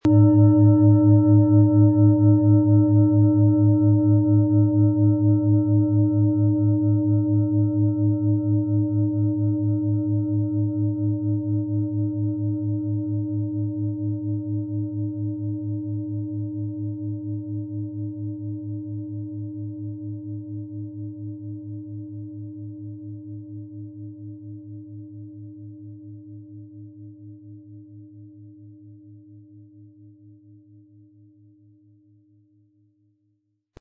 Planetenschale® Fröhlich fühlen & Erfinde Dich neu mit Eros & Uranus, Ø 23,4 cm, 1300-1400 Gramm inkl. Klöppel
• Tiefster Ton: Uranus
Im Sound-Player - Jetzt reinhören hören Sie den Original-Ton dieser Schale.
PlanetentöneEros & Uranus
MaterialBronze